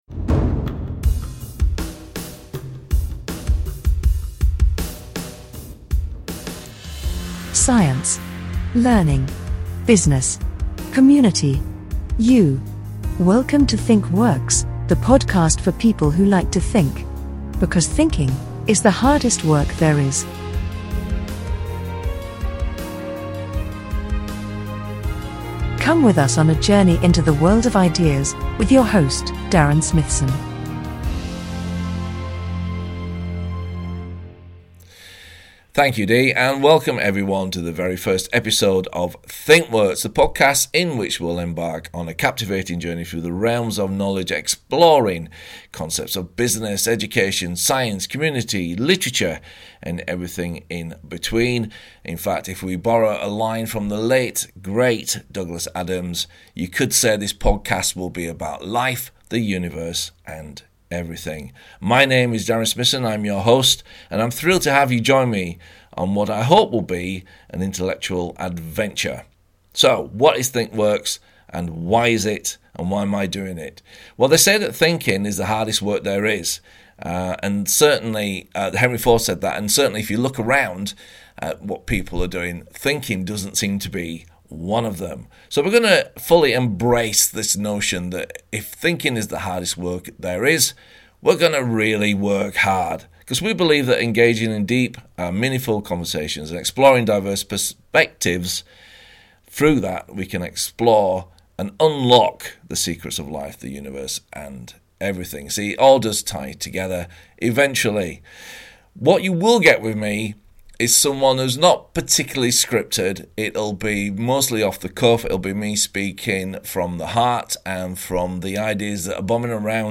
It's a bit raw, but it's genuinely me.